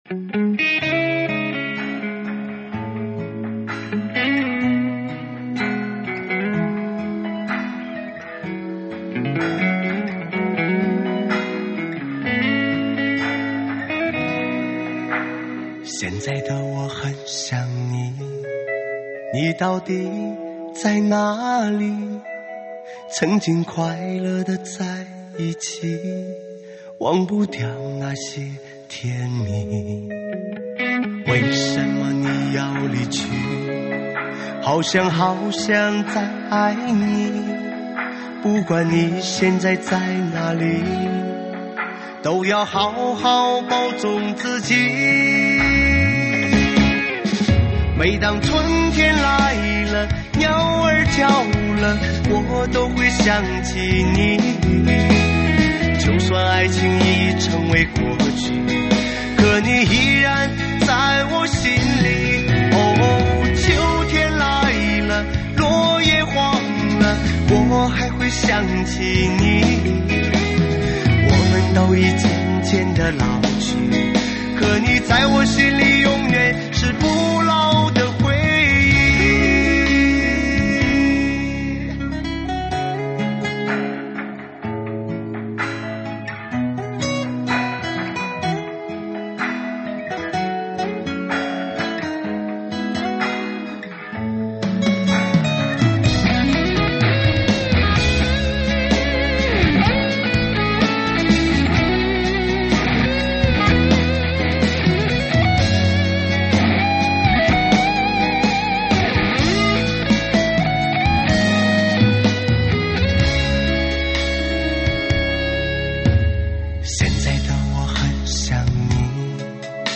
(3D全景环绕)